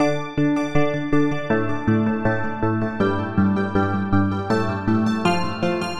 REverby ambient drums
描述：live drums with excessive reverb
标签： Echo reverbdrumsample Reverbdrums
声道立体声